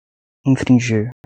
/ĩ.fɾĩˈʒi(ʁ)/